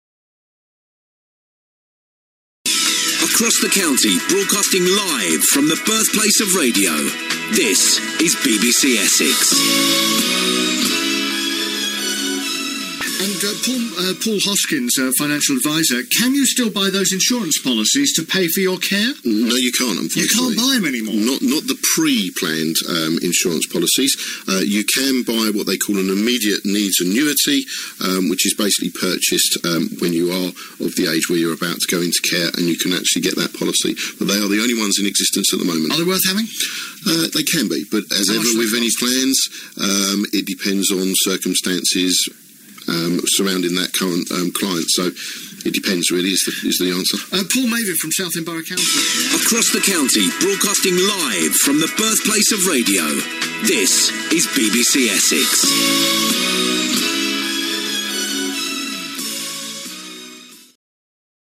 talking live